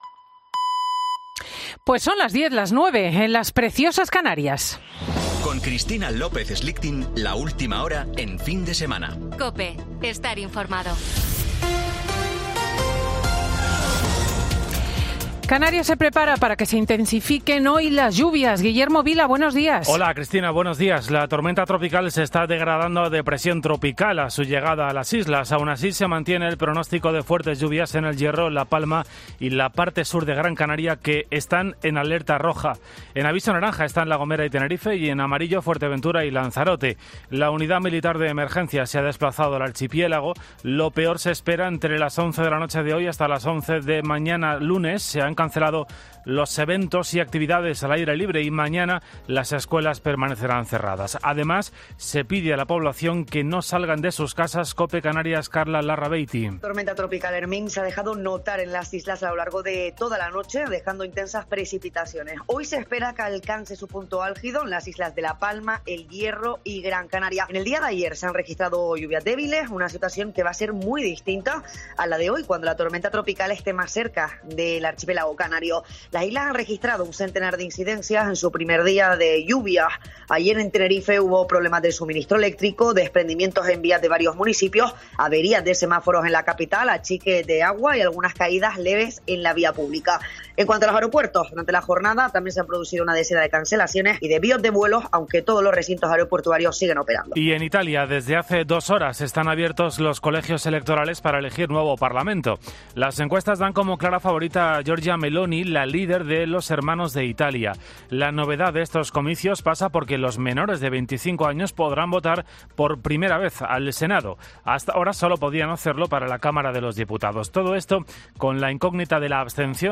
Escucha el monólogo de Cristina López Schlichting de este domingo 25 de septiembre de 2022